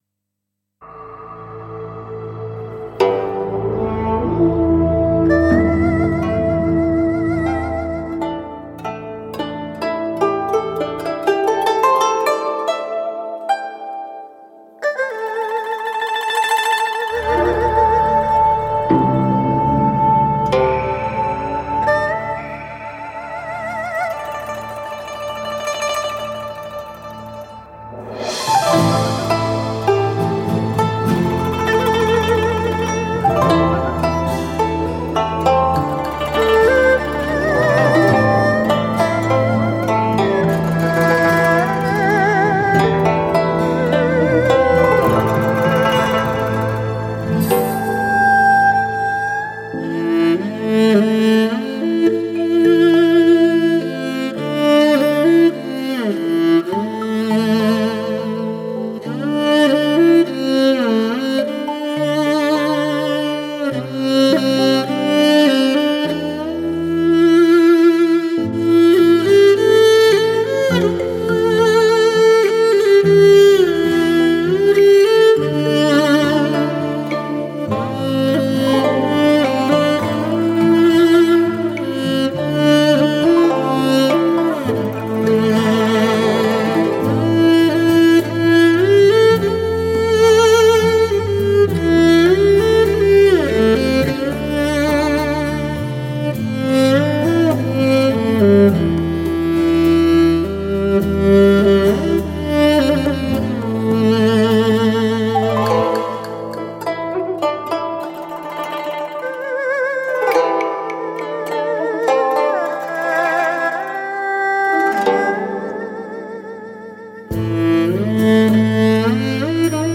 中国（A）德国（STS）美国（HD）联合打造，开创革命性的STS+HD全方位环绕，
Magix Vitual Live高临场感CD.